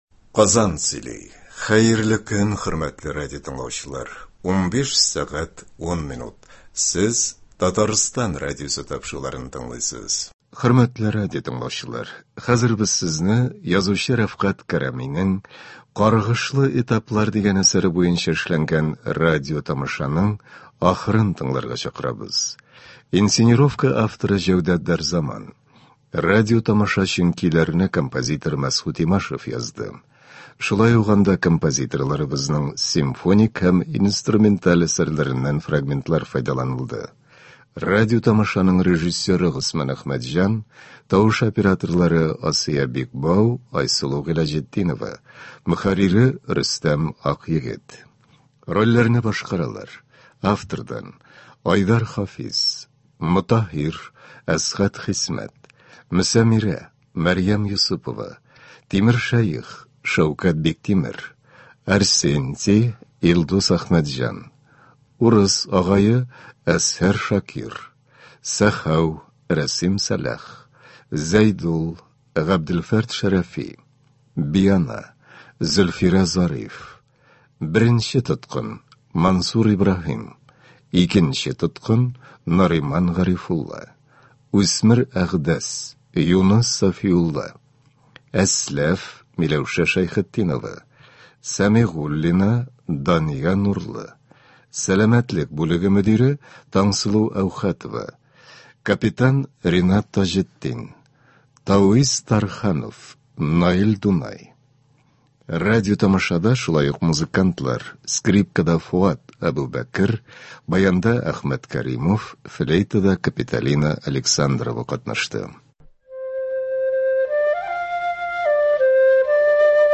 Рәфкать Кәрами. “Каргышлы этаплар”. Радиоспектакль.
Рольләрне Г.Камал исемендәге Татар Дәүләт академия театры артистлары башкара.